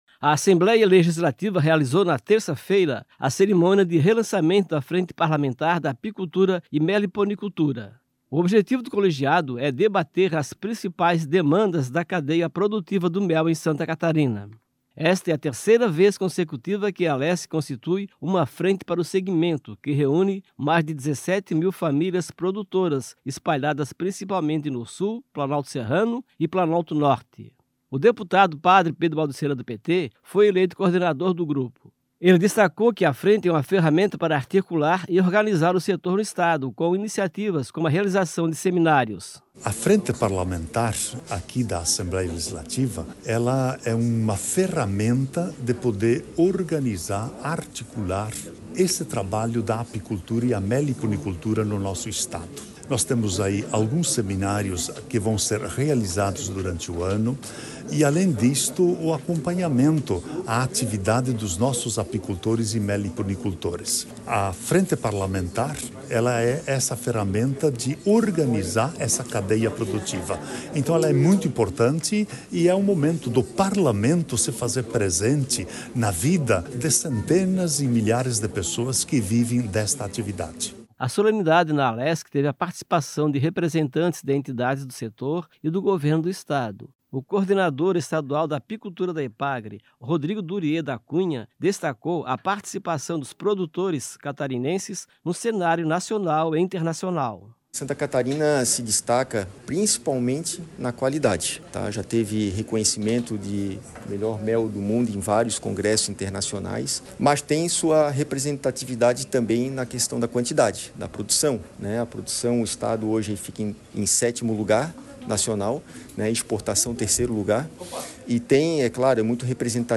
Entrevistas com:
- deputado Padre Pedro Baldissera (PT);